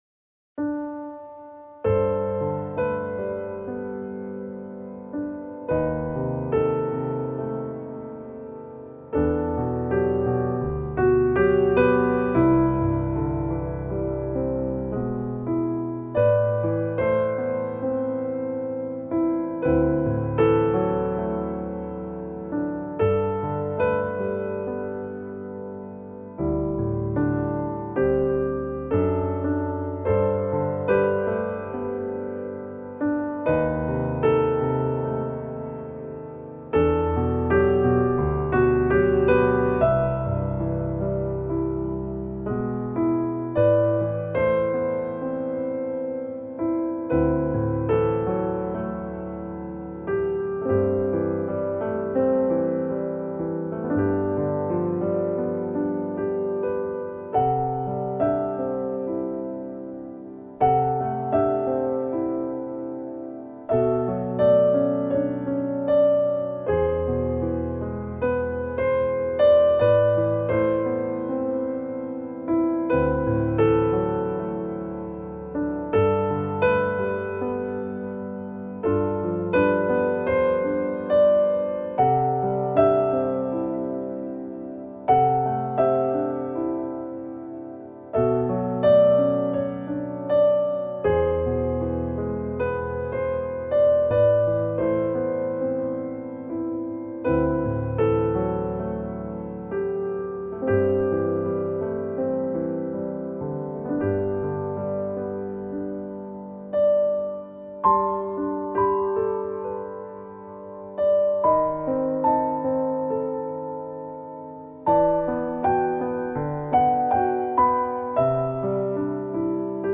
冥想音乐